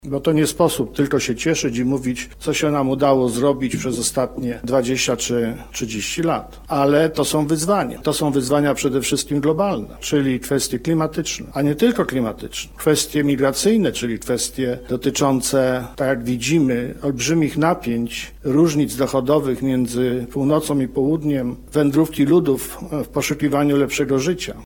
W Centrum Nauki Kopernik zorganizowano debaty z udziałem samorządowców, ekspertów oraz młodych osób działających na rzecz klimatu i edukacji.
– Na kongresie pojawiły się również kwestie dotyczące przyszłości – zaznacza Adam Struzik.